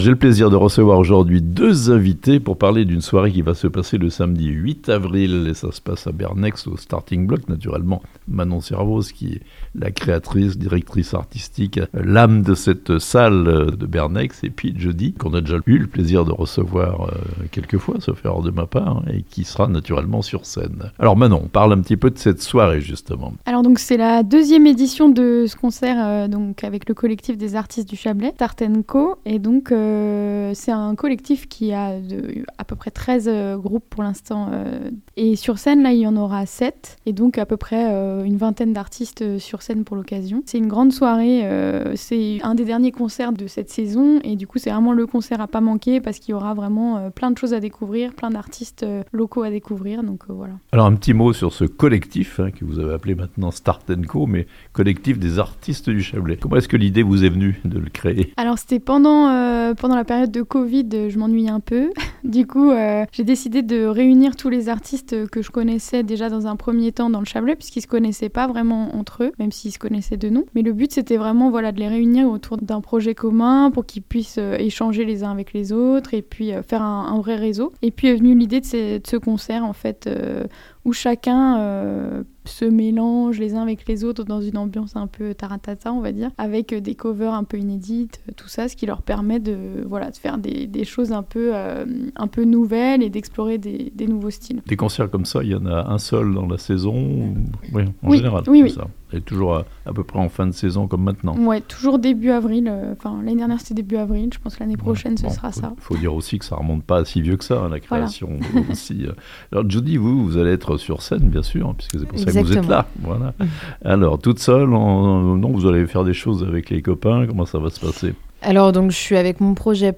Bernex : un concert du collectif des artistes chablaisiens ce samedi 8 avril (interviews)